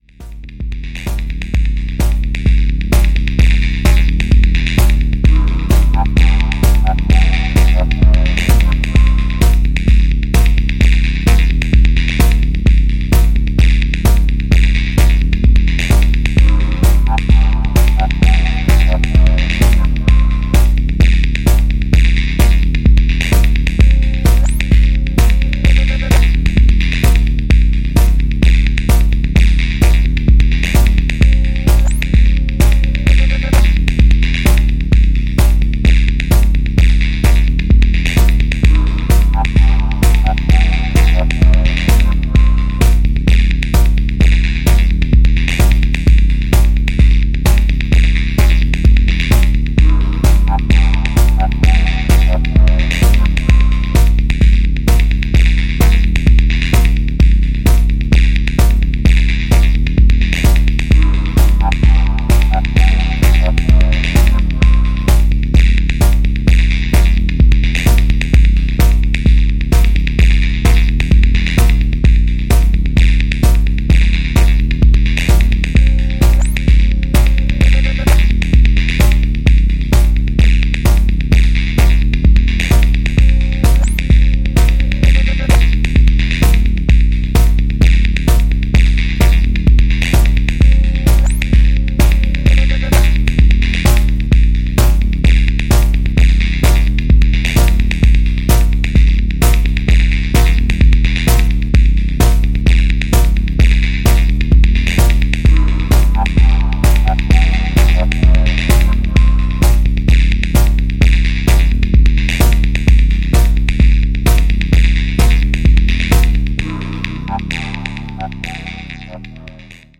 Under Sea electronic Disturbances via Detroit...